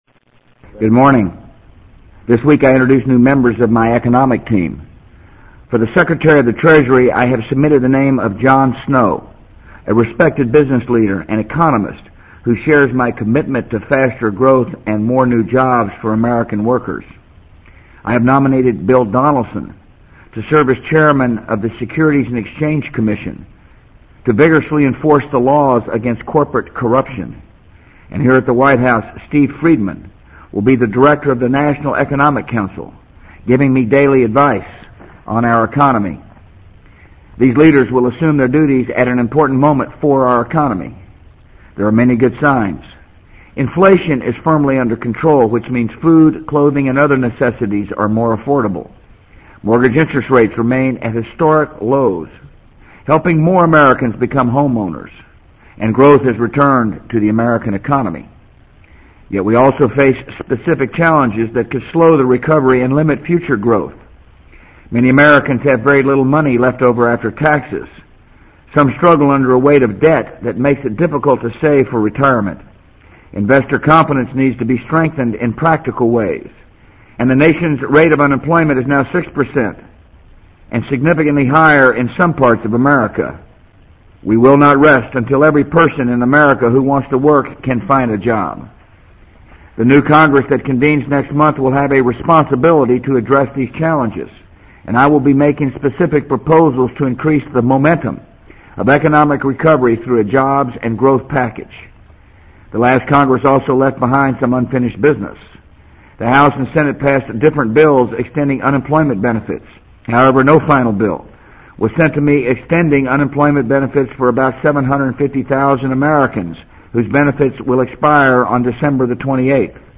【美国总统George W. Bush电台演讲】2002-12-14 听力文件下载—在线英语听力室